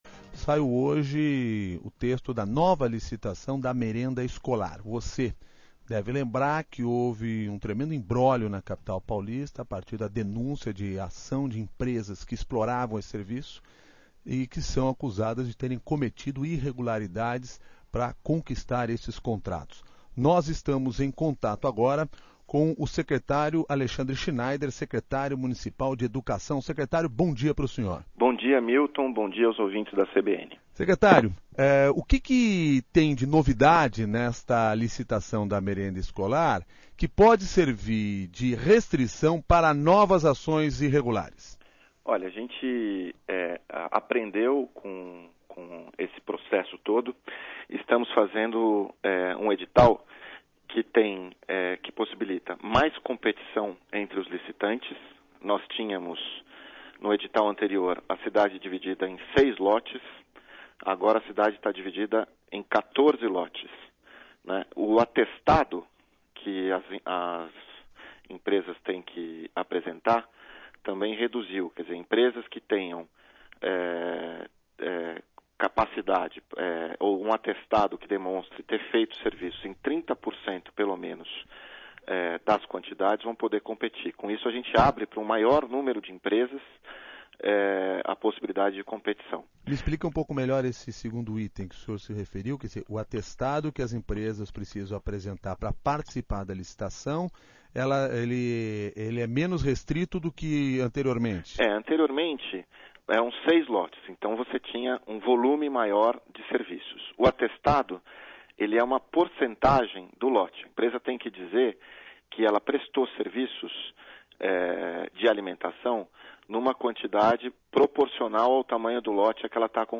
Ouça a entrevista do secretário da Educação Alexandre Schneider
O secretário municipal de Educação, Alexandre Schneider, explicou ao CBN SP as novas regras e como estas podem reduzir o risco de fraudes no serviço de terceirização da merenda escolar.